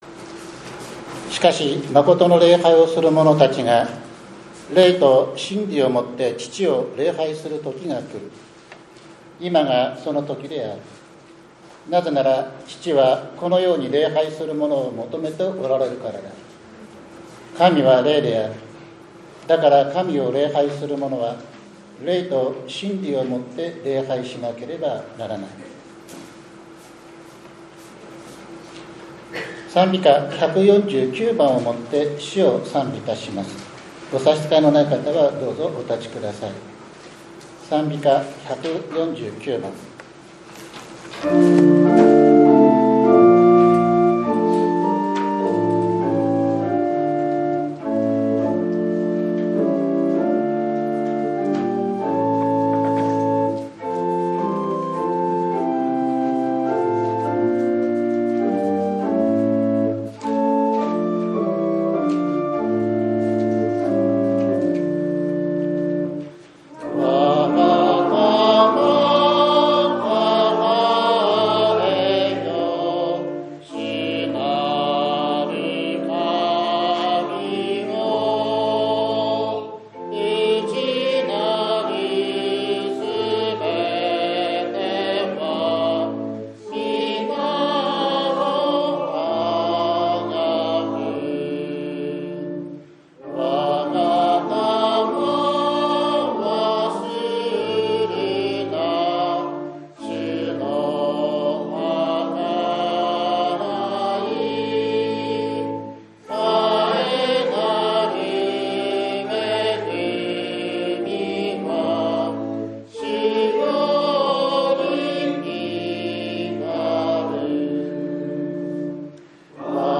６月１日（日）主日礼拝